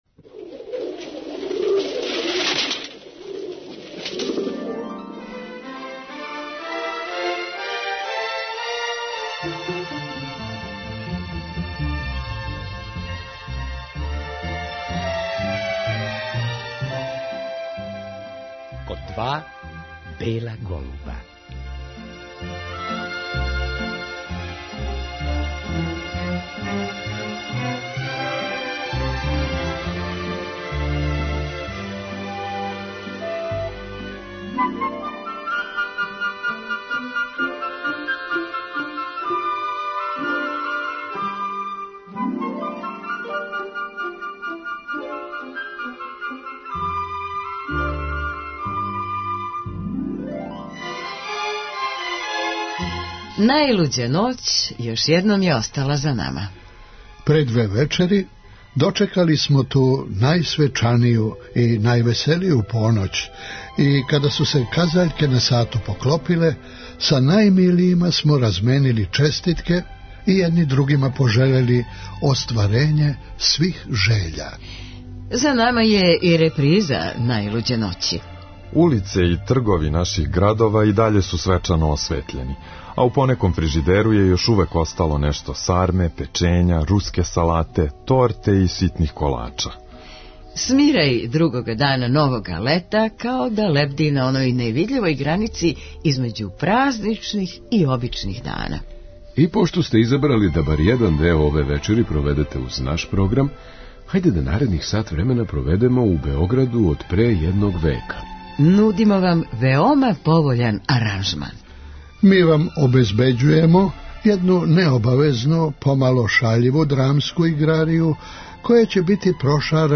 Ми вам обезбеђујемо једну необавезну, помало шаљиву драмску играрију, која ће бити прошарана прикладном и разгаљујућом музиком, а на вама је да се опустите и, надамо се, лепо забавите.